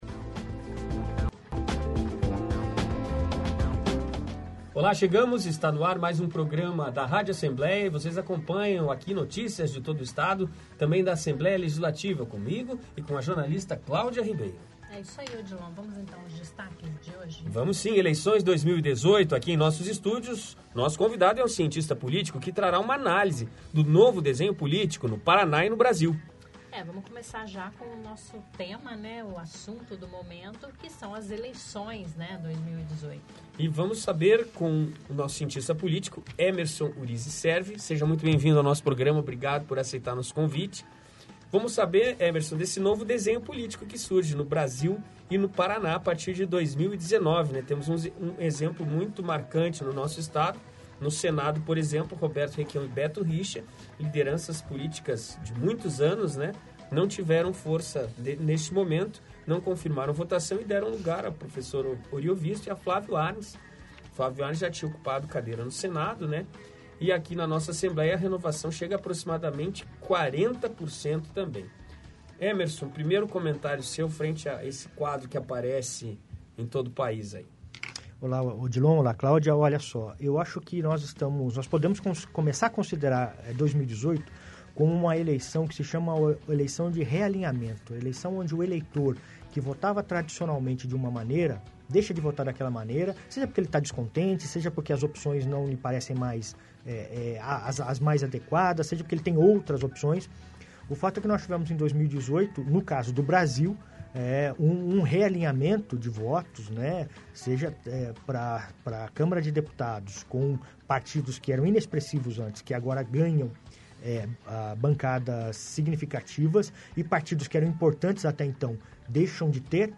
Cientista político analisa resultado das urnas no programa de hoje